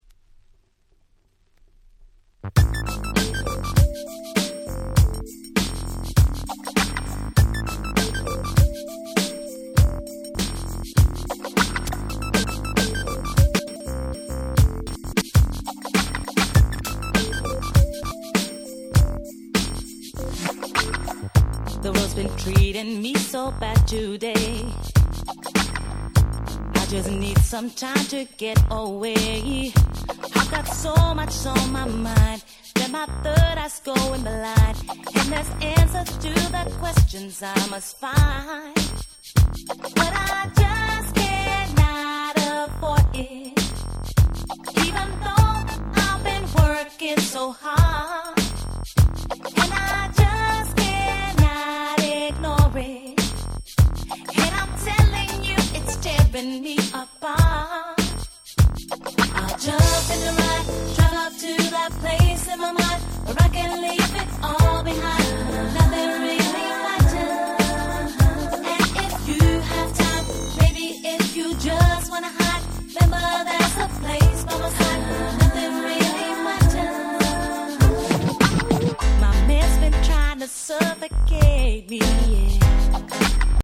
02' Nice UK Soul !!